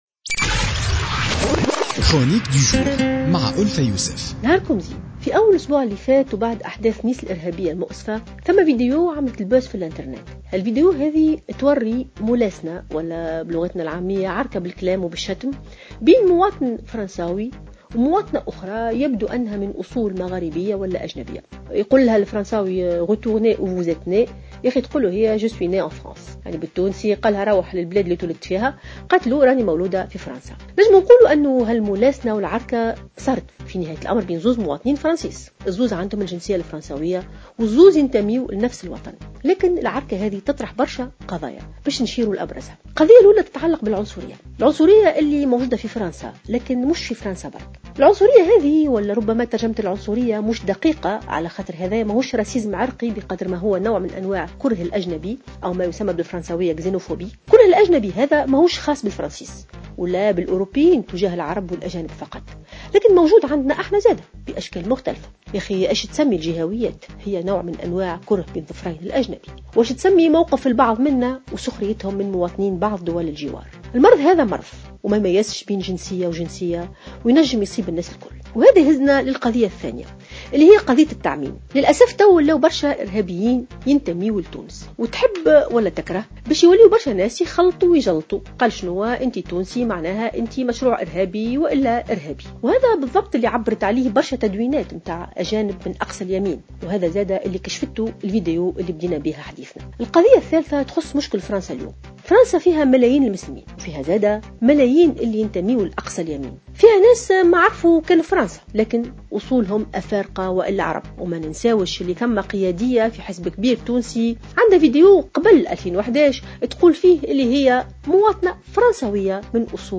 قالت الجامعية ألفة يوسف في افتتاحية اليوم الجمعة إن الهجمات الإرهابية التي شهدتها بعض الدول الغربية خلقت نوعا من التمييز و العنصرية وكره الأجانب للعرب، وفق تعبيرها.